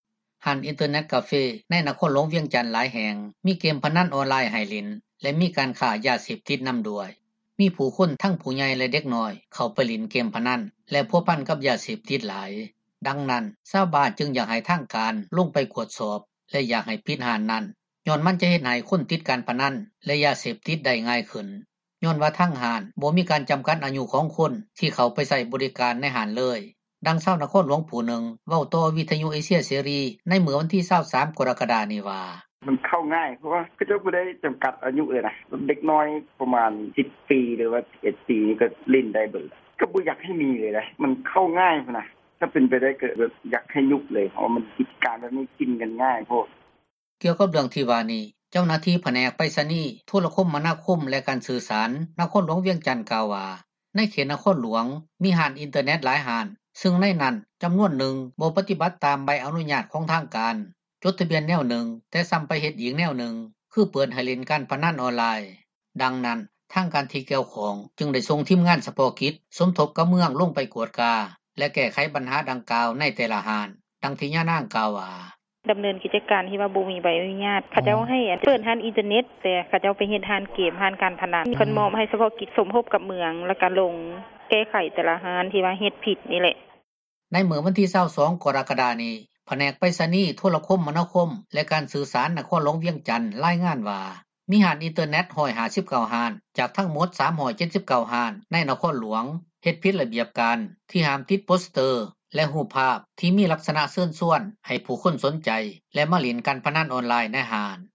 ດັ່ງນັ້ນຊາວບ້ານຈຶ່ງຢາກໃຫ້ທາງການ ລົງໄປກວດສອບ ແລະຢາກໃຫ້ປິດຮ້ານນັ້ນ ຍ້ອນມັນຈະເຮັດໃຫ້ຄົນຕິດ ການພະນັນແລະຢາເສບຕິດ ໄດ້ງ່າຍຂຶ້ນ ຍ້ອນວ່າທາງຮ້ານ ບໍ່ມີການ ຈໍາກັດອາຍຸ ຂອງຄົນທີ່ເຂົ້າໃຊ້ບໍຣິການ ໃນຮ້ານເລີຍ, ດັ່ງຊາວນະຄອນຫລວງ ຜູ້ນຶ່ງ ເວົ້າຕໍ່ວິທຍຸ ເອເຊັຍເສຣີ ໃນມື້ວັນທີ 23 ກໍຣະກະດາ ນີ້ວ່າ: